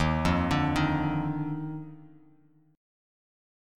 D#M7sus2 Chord